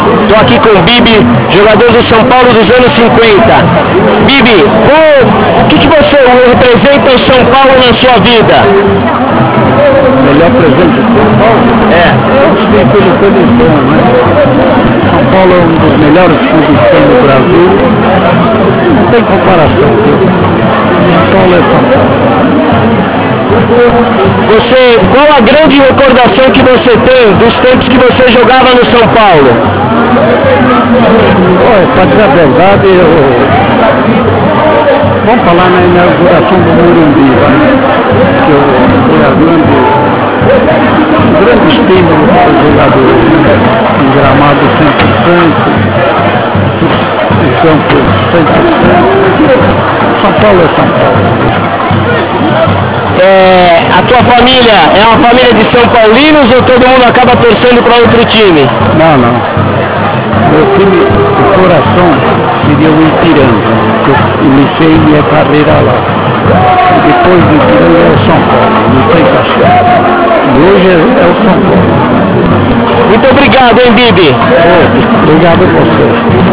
A Equipe Tricolormania esteve presente no IV Encontro de ex-jogadores, fotografando e entrevistando alguns craques que fizeram história com a camisa São-paulina.